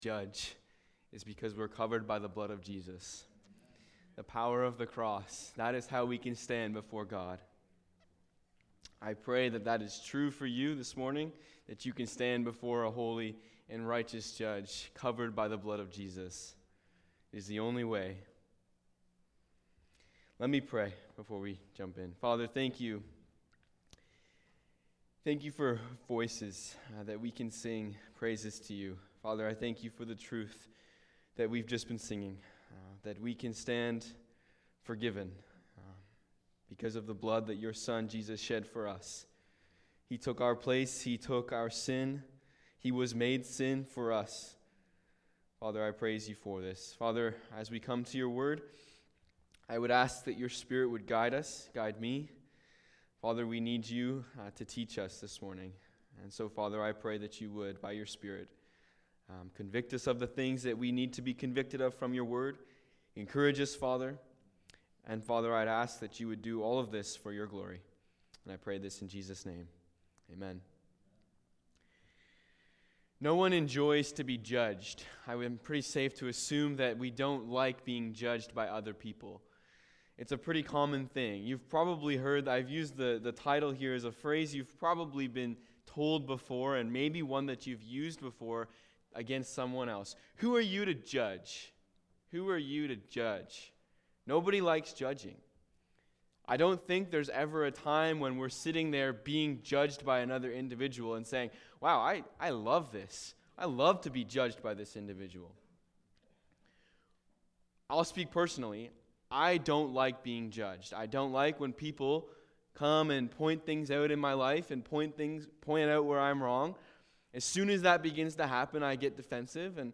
Sermons | Port Perry Baptist